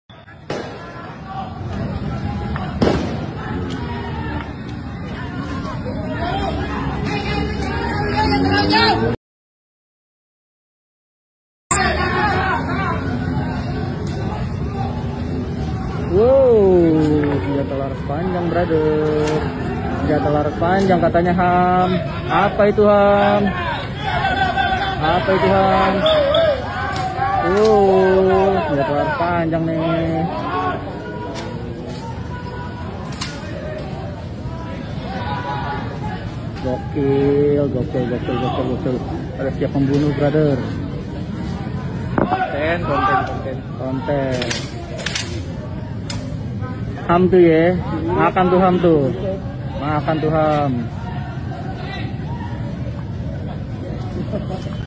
aksi demo saat di jakarta